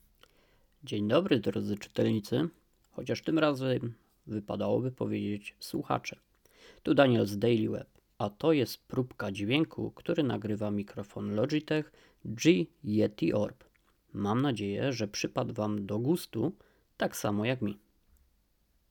Nagrany dźwięk mimo mojej wybuchowej i niepoprawnej wymowy jest całkiem przyjemny. Nie słychać tu żadnych upodleń na jakości lub artefaktów. Poziom dźwięku jest stabilny i łatwy do wyrównania.
Sam głos mimo ostrej korekcji oprogramowania Option+ (można je wyłączyć) brzmi bardzo naturalnie.
Głosówka: